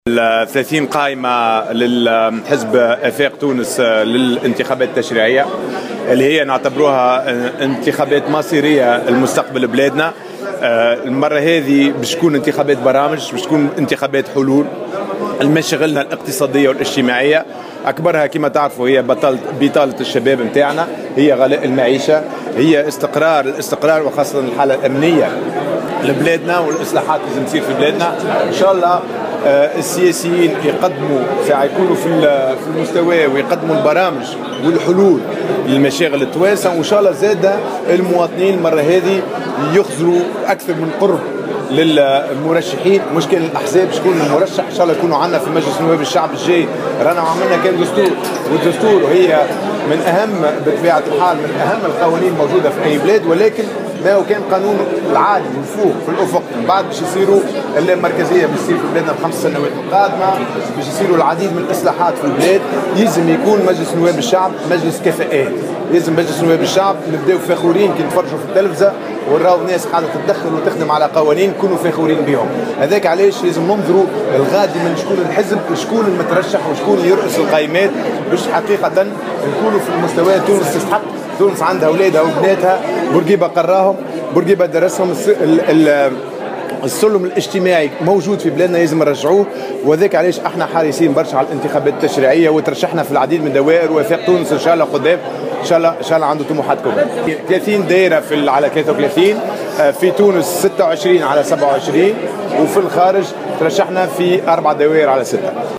أعلن رئيس حزب آفاق تونس، ياسين ابراهيم اليوم الاربعاء خلال ندوة صحفية عن قائمات الحزب للانتخابات التشريعية والبالغ عددها 30 قائمة.